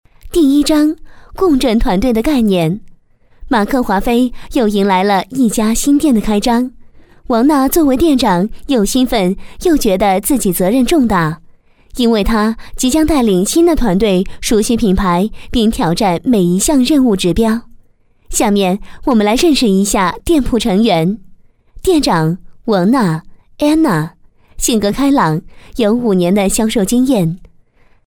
女声配音
课件女国47B